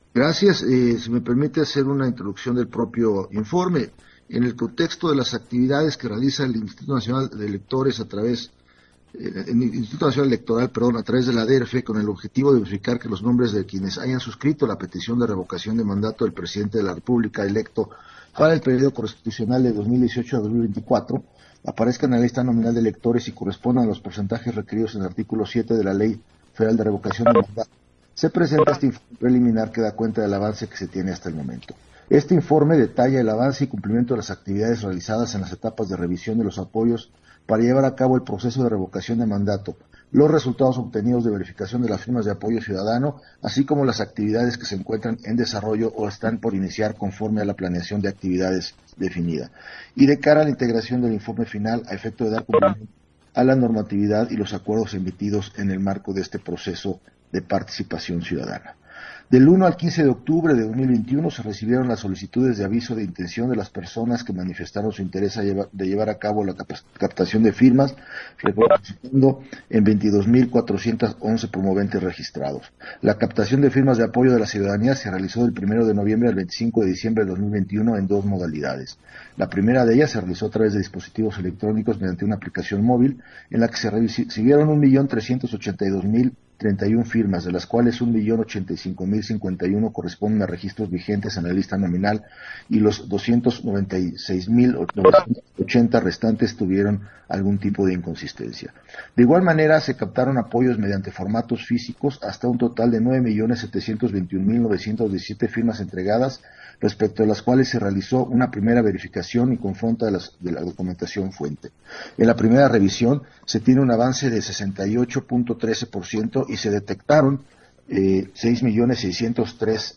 Intervención de René Miranda, en la Comisión del Registro Federal de Electores, relativo al informe preliminar del proceso de verificación de firmas para la Revocación de Mandato